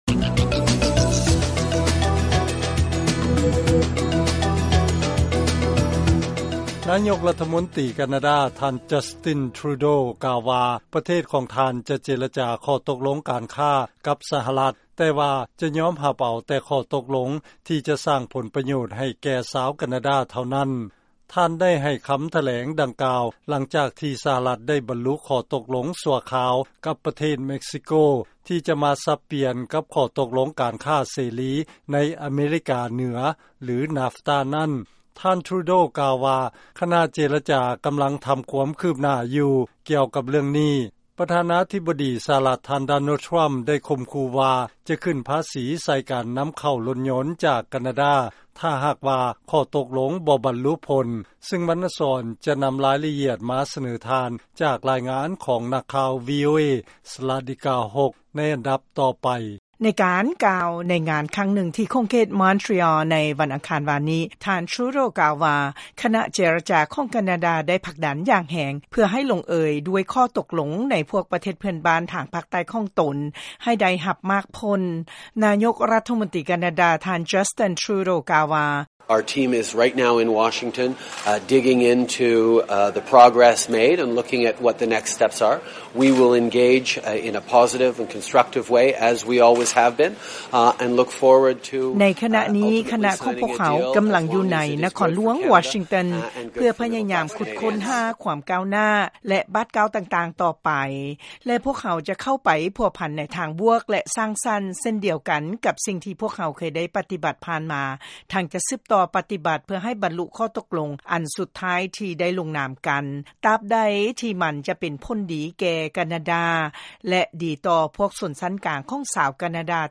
ລາຍງານກ່ຽວກັບ ຂໍ້ຕົກລົງການຄ້າລະຫວ່າງ ສະຫະລັດ ກັບ ເມັກຊິໂກ